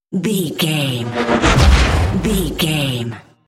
Dramatic chopper to hit 649
Sound Effects
Atonal
intense
tension
woosh to hit
the trailer effect